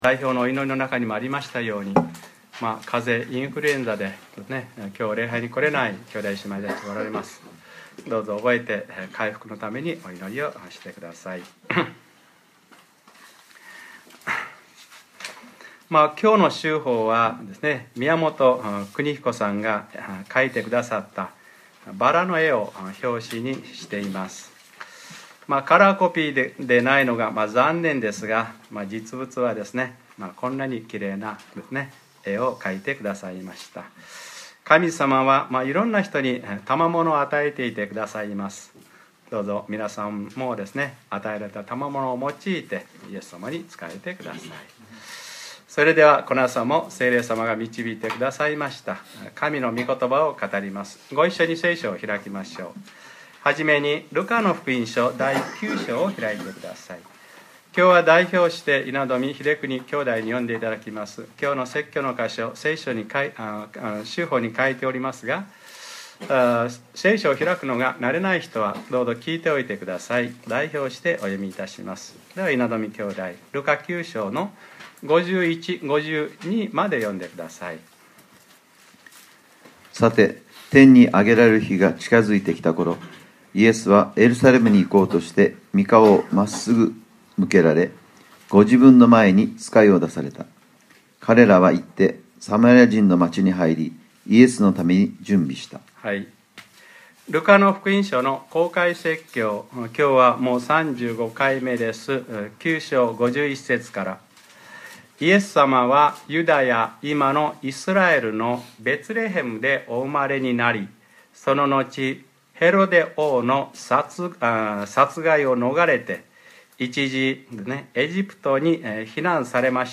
2014年 2月16日（日）礼拝説教『ルカ-３５：人の子には枕する所もありません』